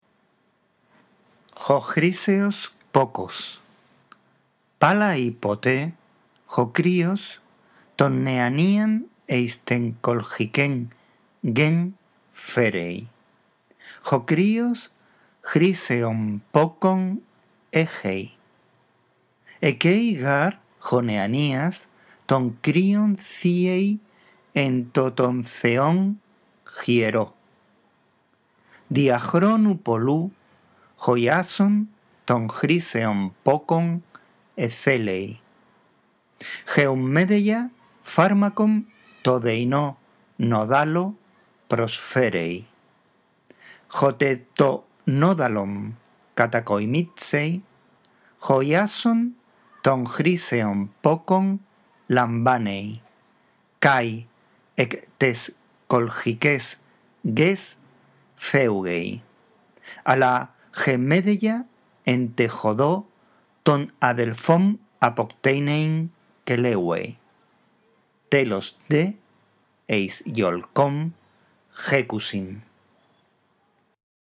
La audición de este archivo contribuirá a la práctica de la lectura del griego